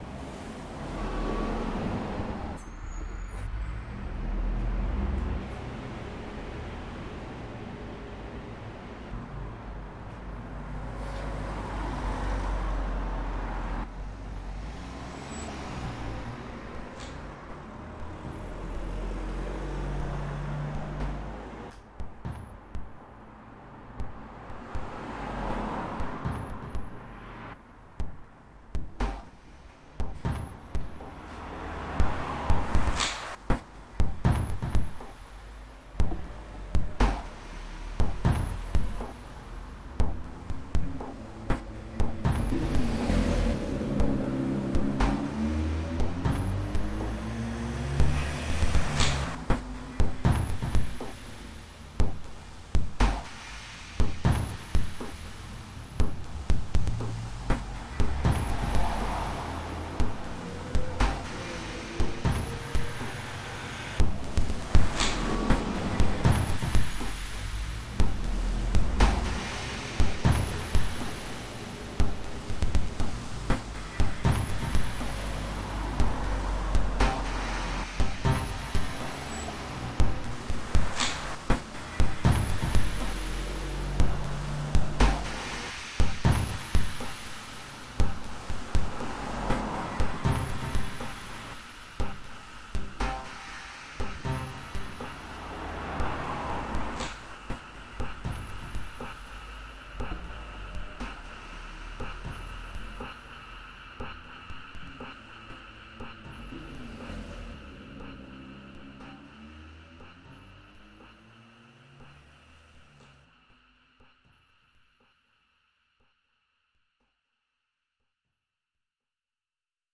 reimagines a sound from Lansdowne Road, Bath